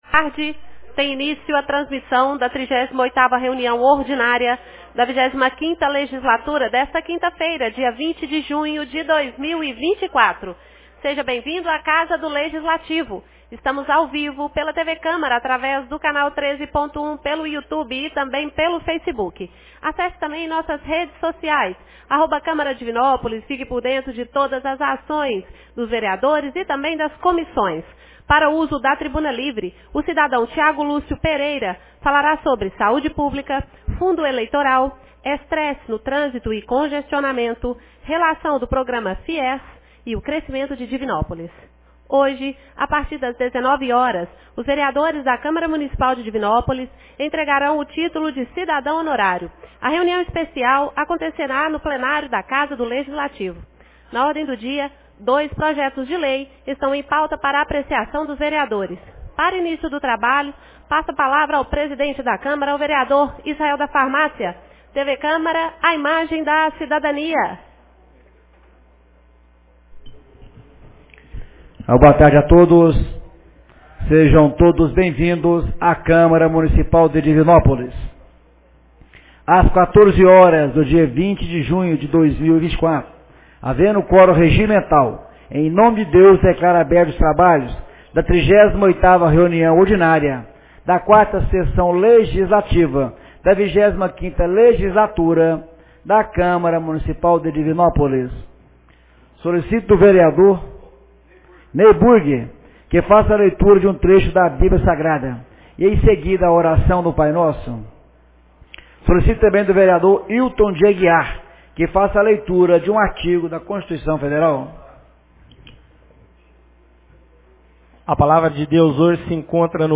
38ª Reunião Ordinária 20 de junho de 2024